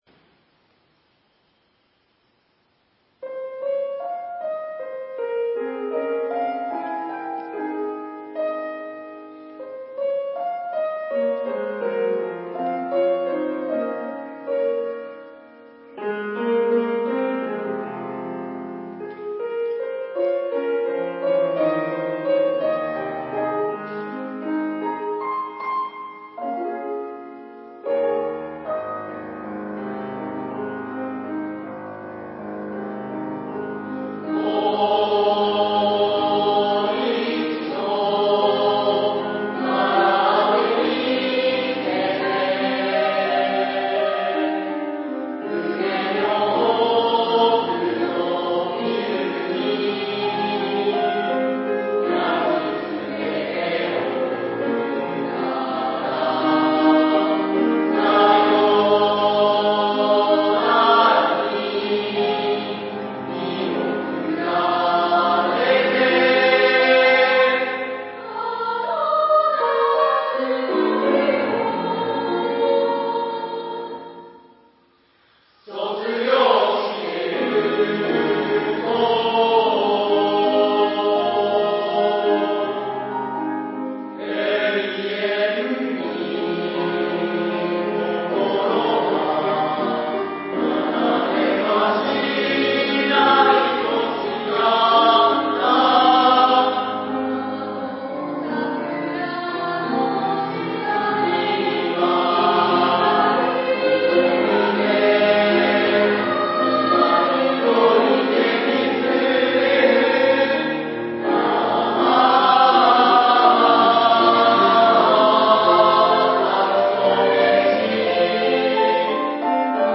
3年合唱コンサート
11月16日，17日に合唱コンサートを行いました。コロナ対策のため，時間をずらして学級ごと合唱を行いました。
HPに掲載する関係で音質が落としてありますが，ぜひお聞きください。